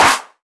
• Hand Clap C Key 12.wav
Royality free clap sound - kick tuned to the C note. Loudest frequency: 2205Hz
hand-clap-c-key-12-HCJ.wav